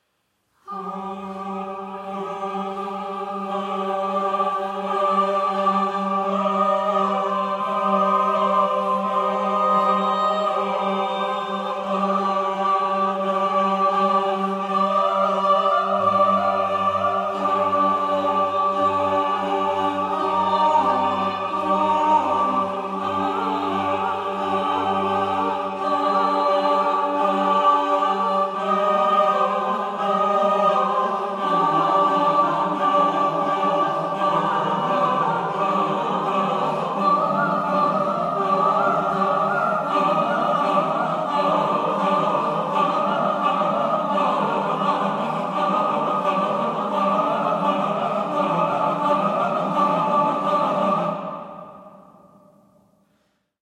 • Genres: Classical, Vocal
choral works
The album’s other wordless setting
ominous